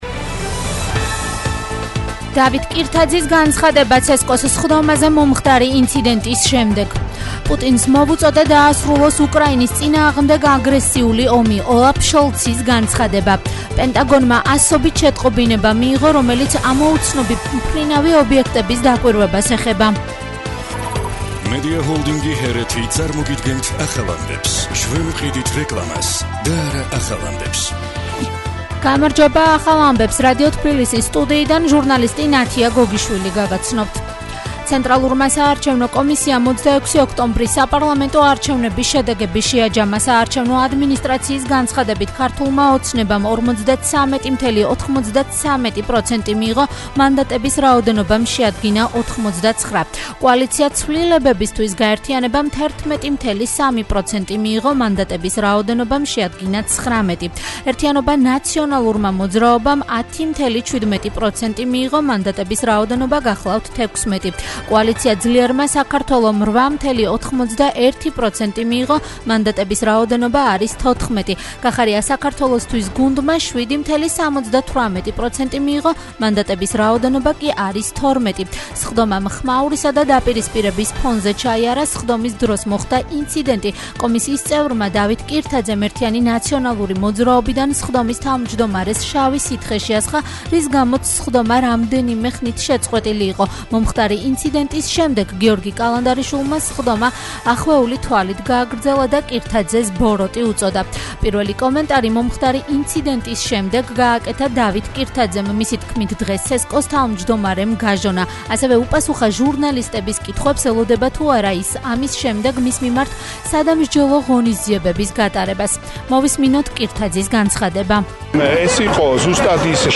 ახალი ამბები 13:00 საათზე